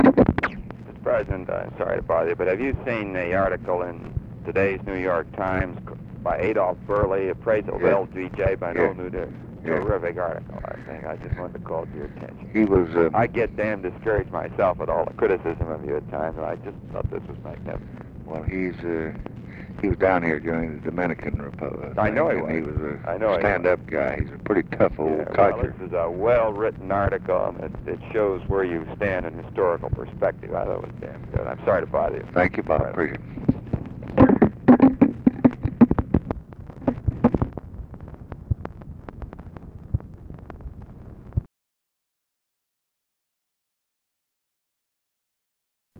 Conversation with ROBERT MCNAMARA, January 14, 1967
Secret White House Tapes